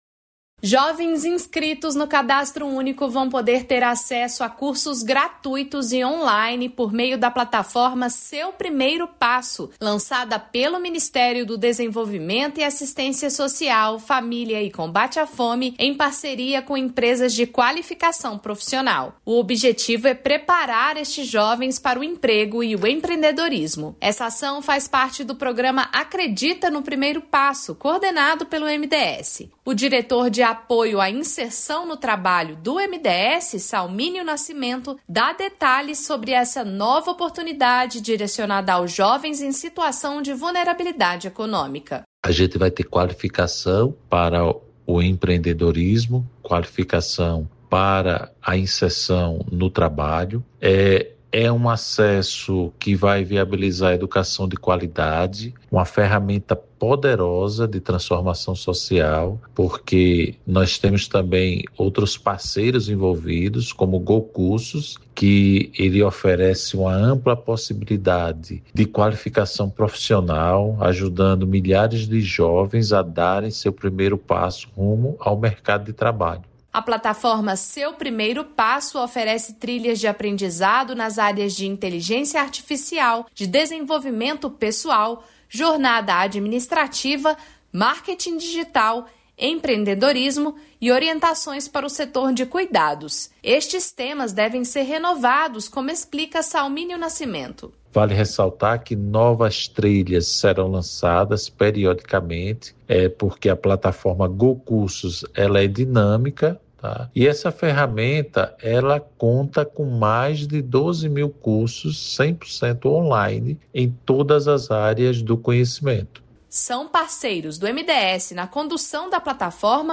Boletim do MDS